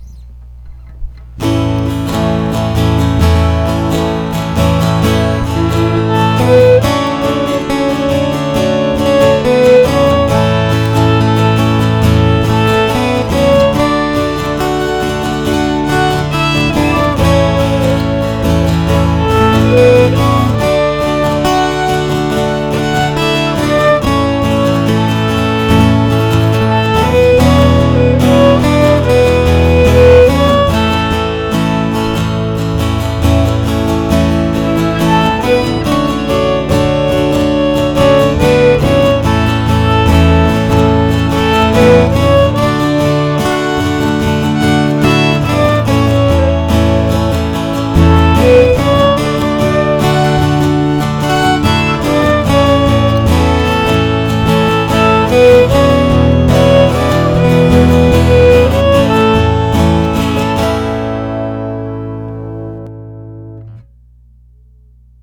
This is not completely violin either.
Self-duet time!
I think it's cool when one can wright the backing track and practice playing along with it.
I loved your guitar/violin of "You Are My Sunshine".
You-Are-My-Sunshine-Duet.wav